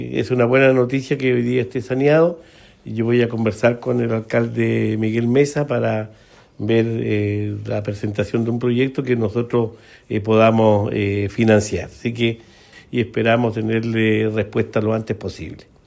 gobernador-cementerio-comunidad.mp3